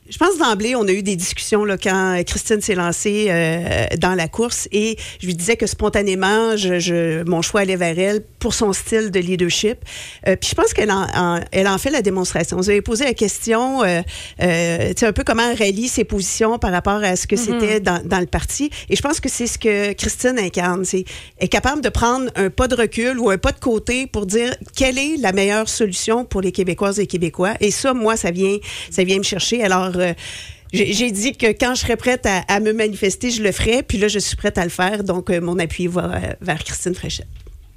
La députée de Brome‑Missisquoi, ministre responsable du Sport, du Loisir et du Plein air ainsi que de la région de l’Estrie, Mme Isabelle Charest, de passage dans nos studios jeudi, a annoncé qu’elle appuie Mme Christine Fréchette dans la course à la chefferie.